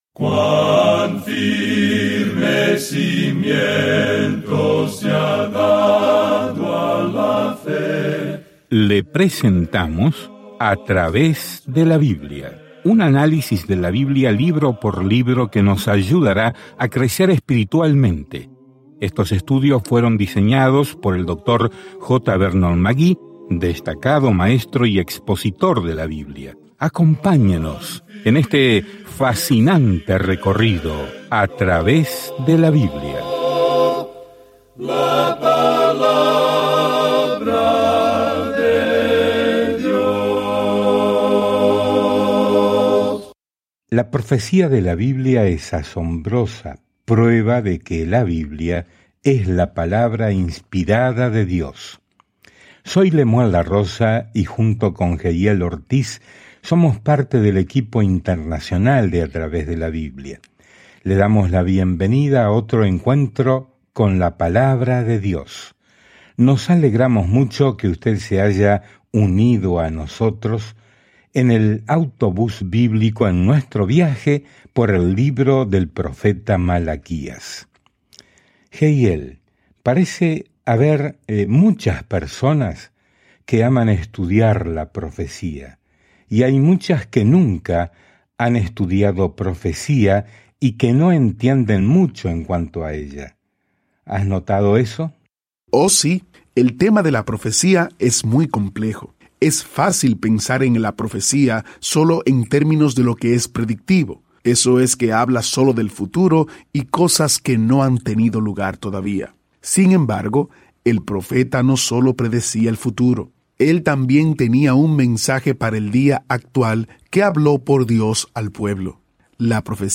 Escrituras Malaquías 3:4-7 Día 10 Comenzar este Plan Día 12 Acerca de este Plan Malaquías le recuerda a un Israel desconectado que tiene un mensaje de Dios antes de que soporten un largo silencio, que terminará cuando Jesucristo entre en escena. Viaje diariamente a través de Malaquías mientras escucha el estudio de audio y lee versículos seleccionados de la palabra de Dios.